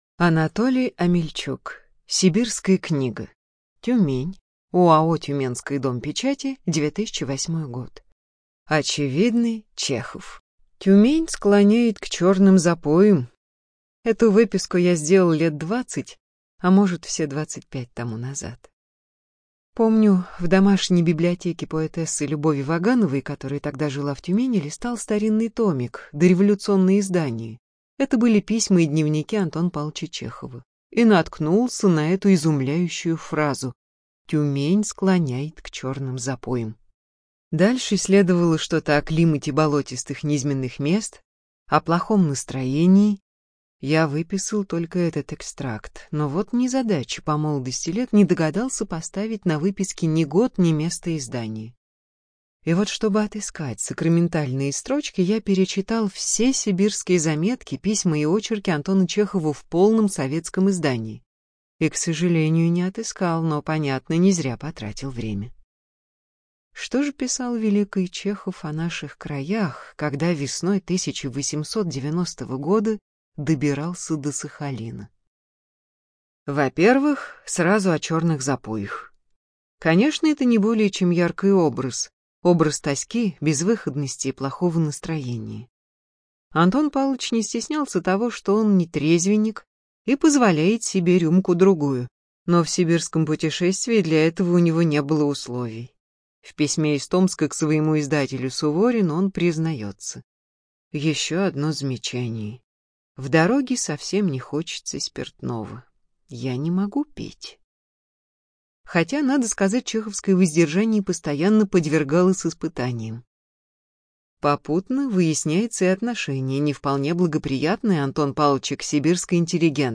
Студия звукозаписиТюменская областная библиотека для слепых